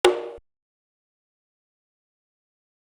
Whe_Perc4.wav